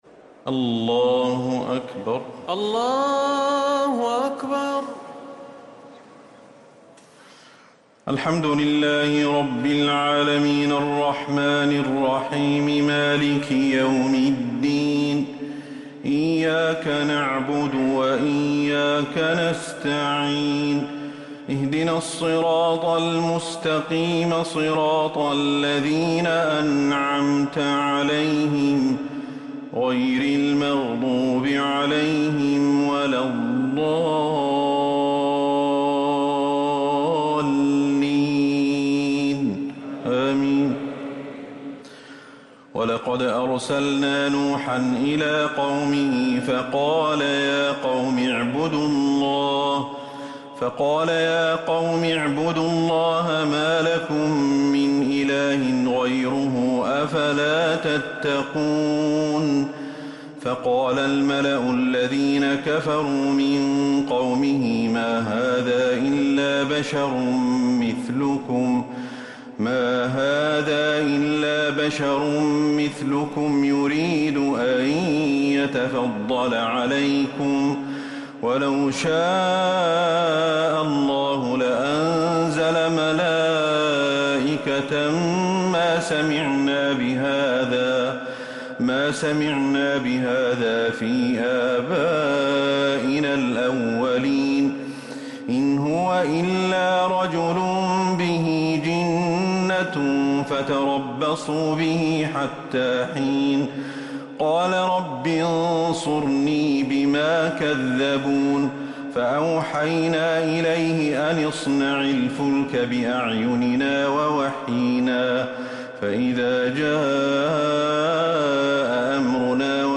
تراويح ليلة 22 رمضان 1444هـ من سورة المؤمنون (23-92) | Taraweeh 22th night Ramadan 1444H Surah Al-Muminoon > رمضان 1444هـ > التراويح